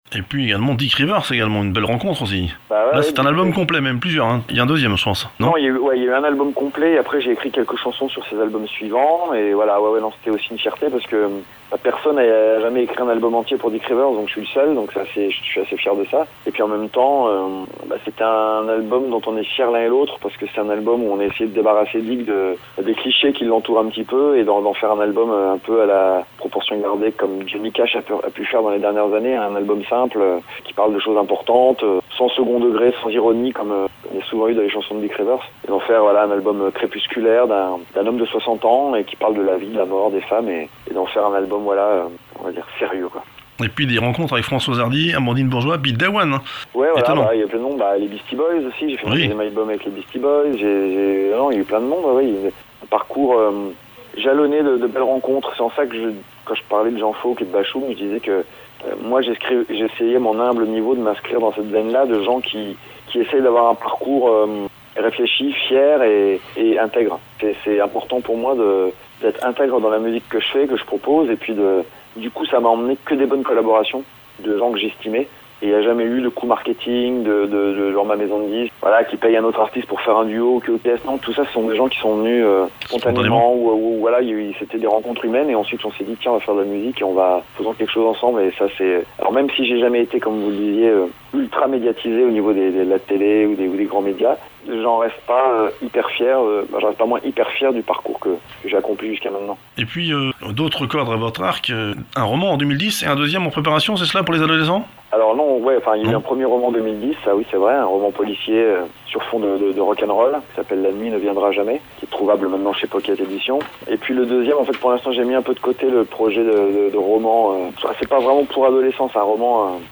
Interview de Joseph d’Anvers (réalisée par téléphone lors de la sortie de son 4ème album « Les Matins Blancs » courant 2015 mais toujours d’actualité !)